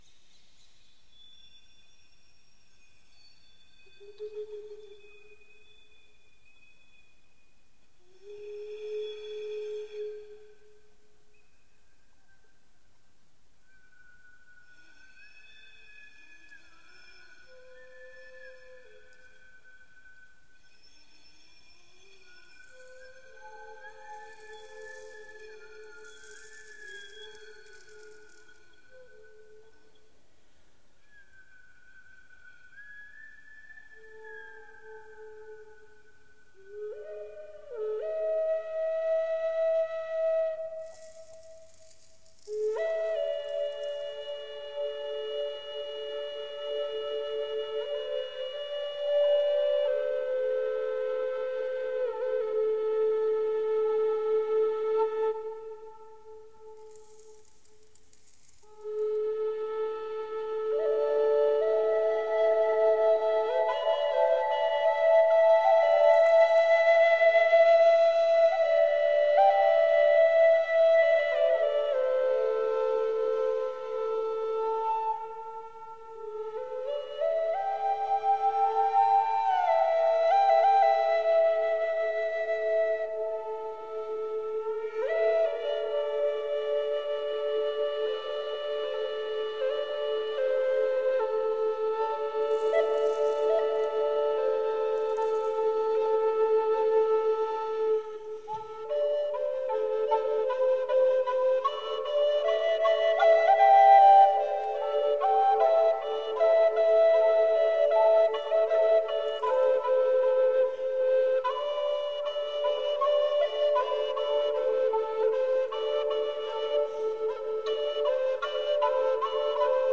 Virtuosic flutist.
Soothing and luminescent soliloquies for flute.
Tagged as: World, New Age, Instrumental, Flute, Massage